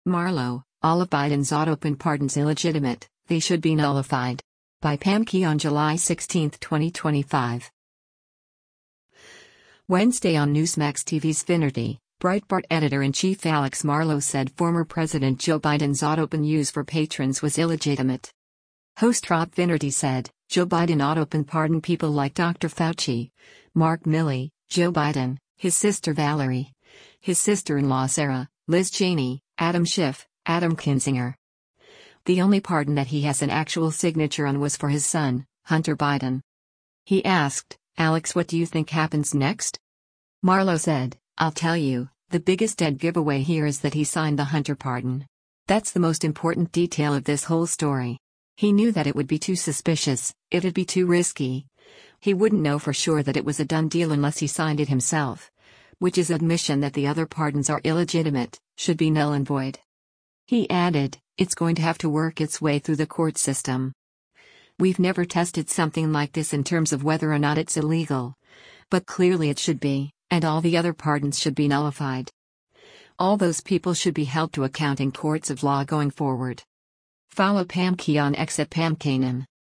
Wednesday on Newsmax TV’s “Finnerty,” Breitbart Editor-in-Chief Alex Marlow said former President Joe Biden’s autopen use for patrons was “illegitimate.”